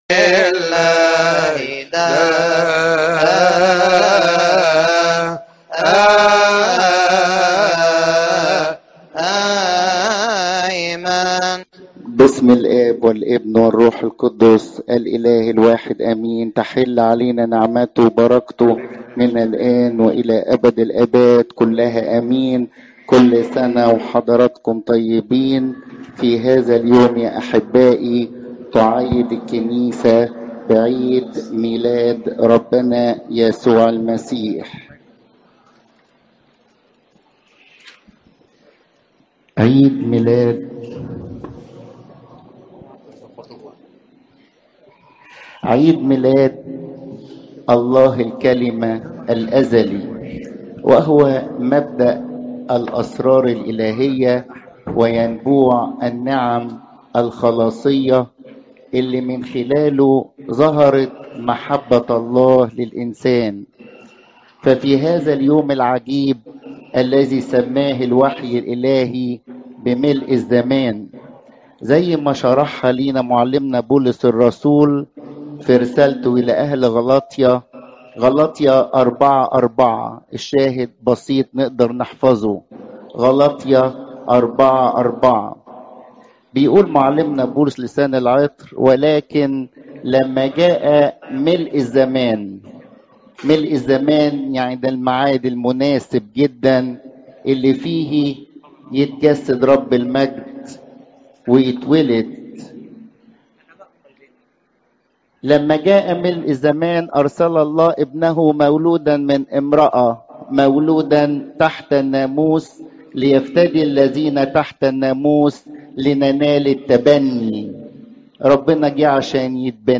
عظات المناسبات عيد الميلاد (مت 2 : 1 - 12)